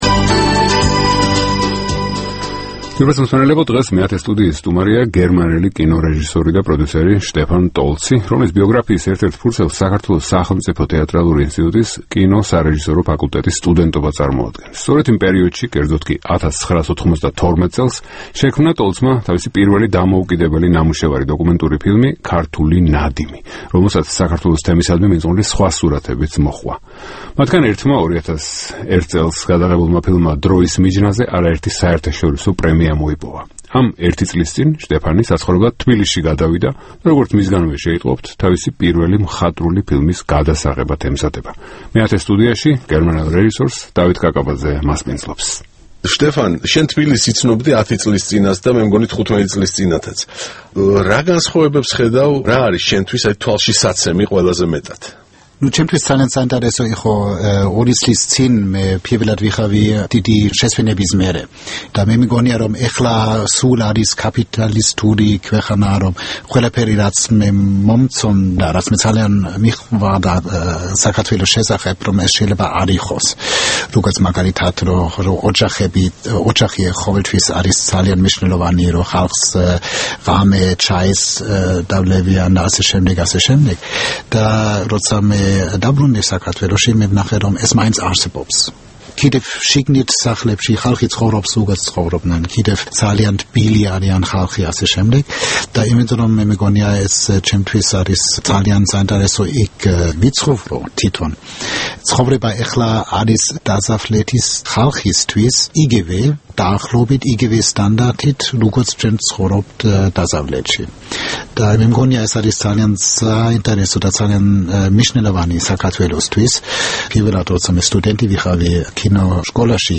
ინტერვიუ გერმანელ კინორეჟისორთან